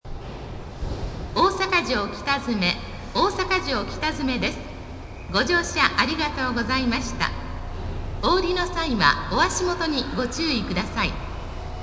北新地駅 1番線接近放送 1番線電車到着時の放送 2番線接近放送 2番線電車到着時の放送 大阪城北詰駅 1番線接近放送 1番線電車到着時の放送 2番線接近放送 2番線電車到着時の放送 京橋駅 京橋駅では、大阪環状線以外で唯一発車時のベルが使用されています。
osakajokitazume_2av.mp3